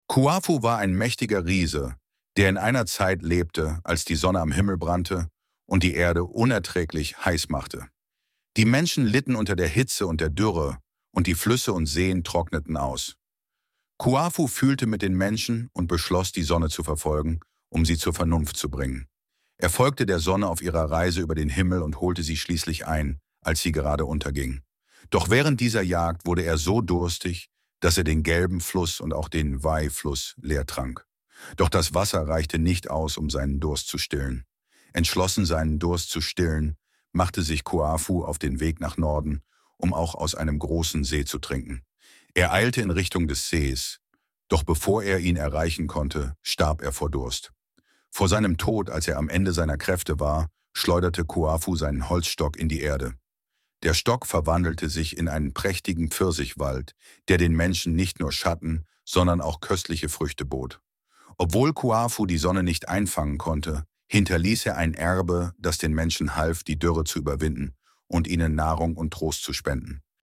Hörfassung: Wer die Legende nicht kennt, kann sie sich hier anhören. Wir haben die Hörversion mit Hilfe von KI erstellt.